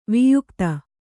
♪ viyukta